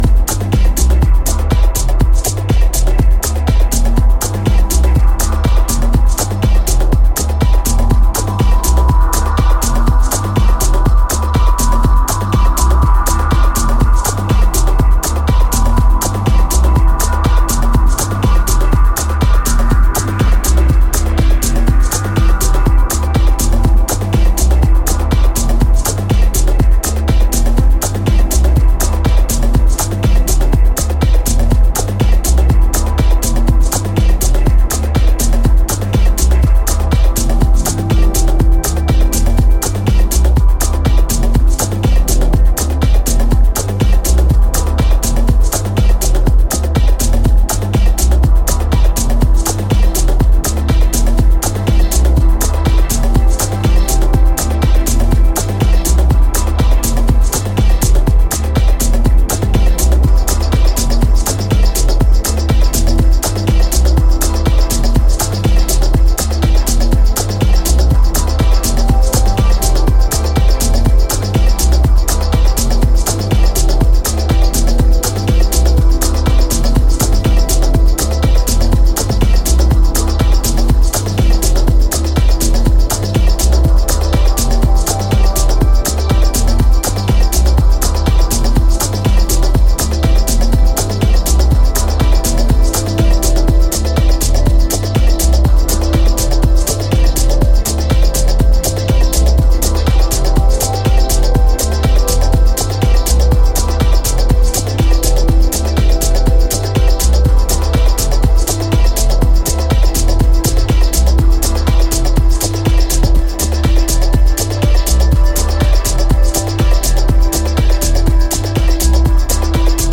Here we have three super-deep yet driving cuts